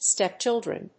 /ˈstɛˈptʃɪldrʌn(米国英語), ˈsteˈptʃɪldrʌn(英国英語)/